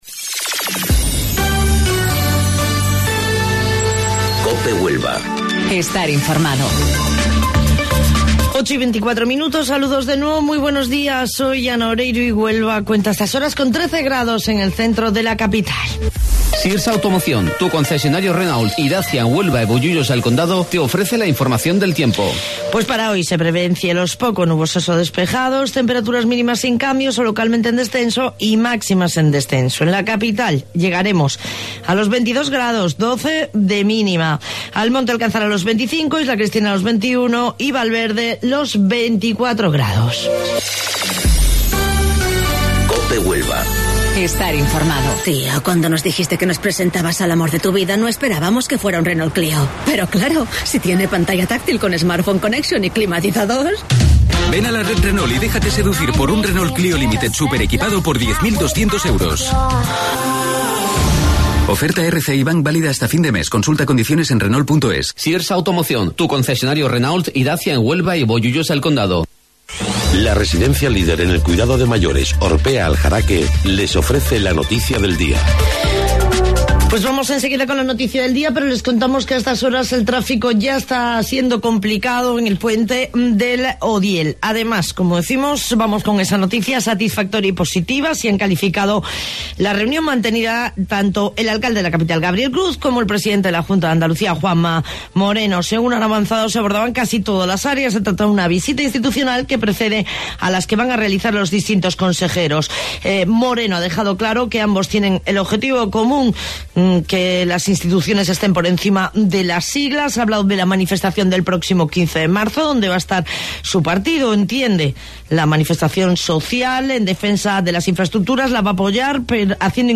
AUDIO: Informativo Local 08:25 del 12 de Marzo